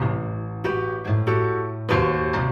Index of /musicradar/gangster-sting-samples/95bpm Loops
GS_Piano_95-A1.wav